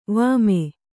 ♪ vāme